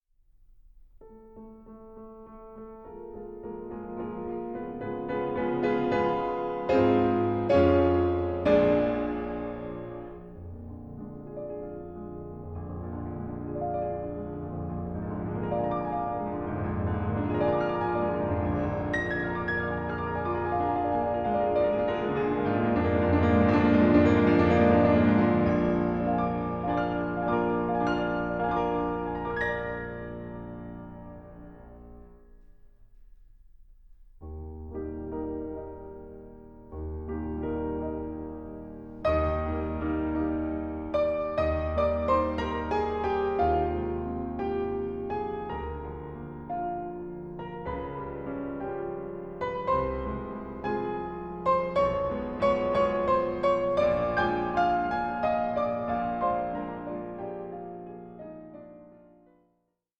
Suite for Piano Duo